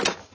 SFX_Menu_Remove_Part.wav